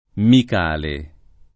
[ mik # le ]